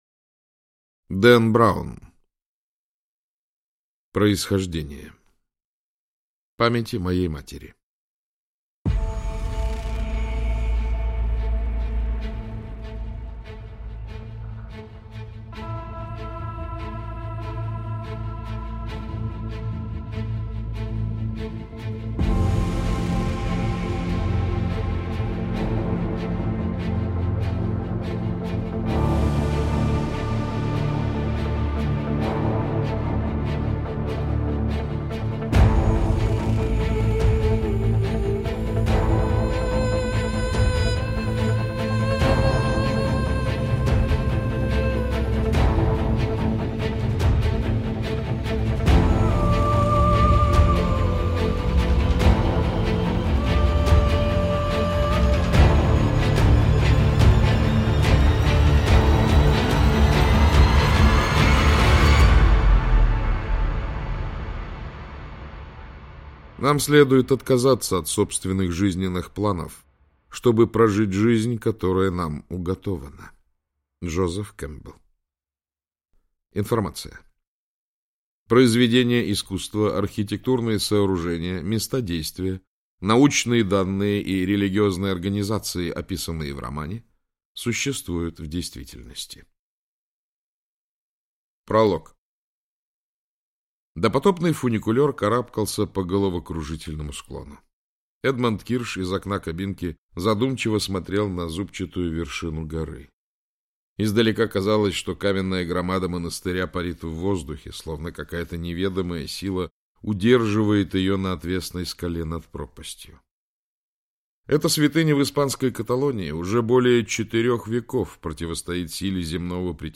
Аудиокнига Происхождение - купить, скачать и слушать онлайн | КнигоПоиск